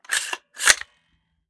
5.56 M4 Rifle - Cocking Slide 002.wav